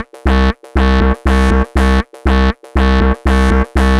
TSNRG2 Off Bass 008.wav